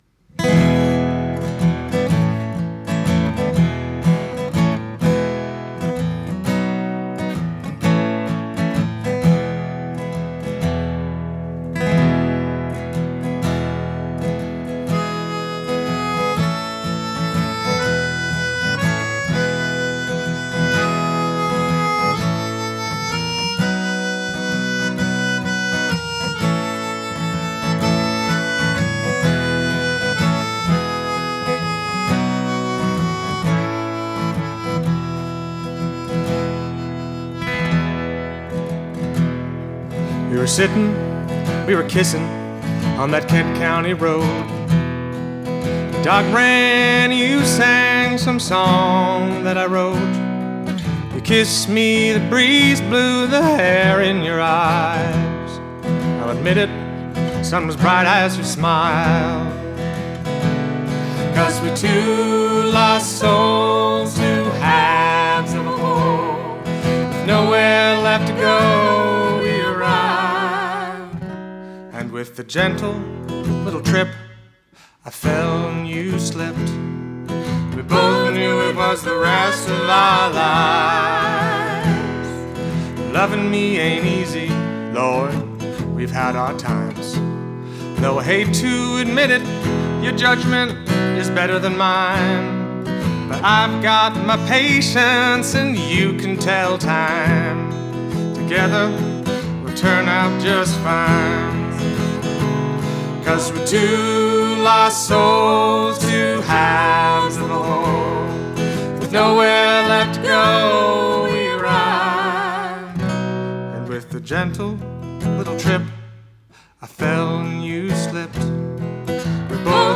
These are demos y'all.